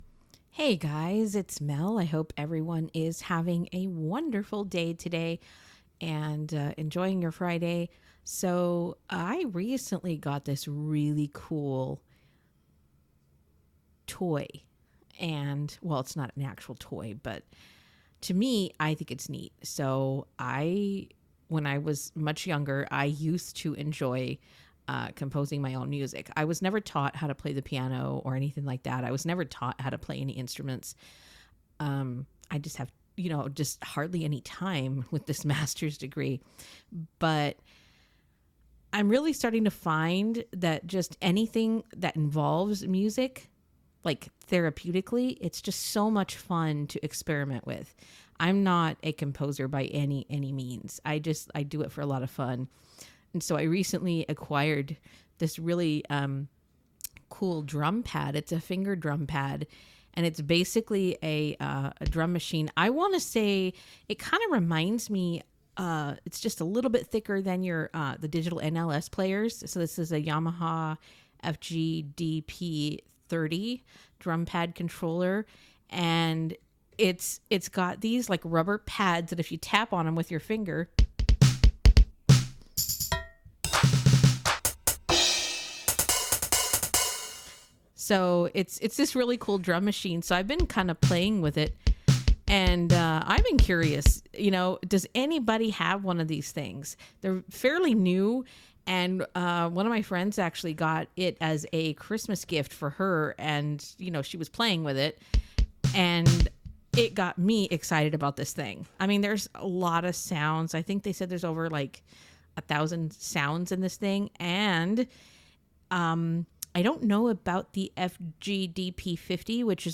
Here's a short clip of me playing with my coolest new gadget, a finger drum pad from Yamaha.